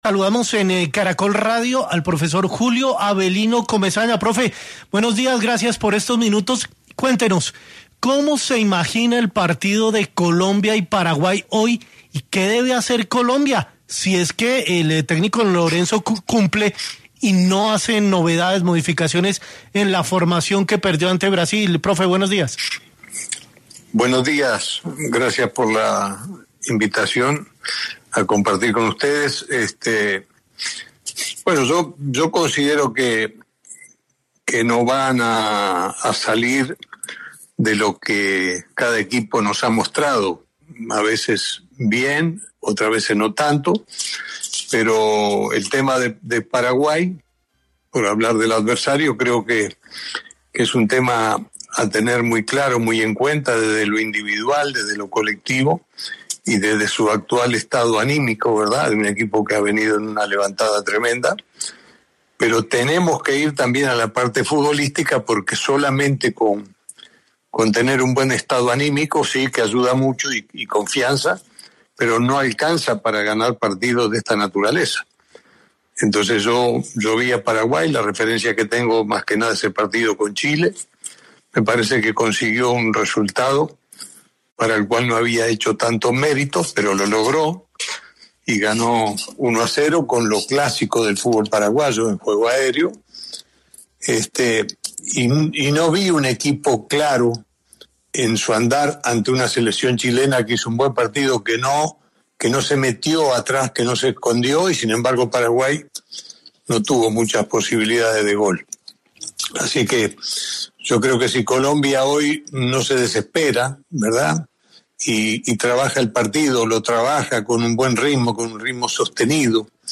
En 6AM de Caracol Radio estuvo Julio Avelino Comesaña, ex entrenador del Junior de Barranquilla, quien habló sobre el presente de la ‘tricolor’ en las Eliminatorias mundialistas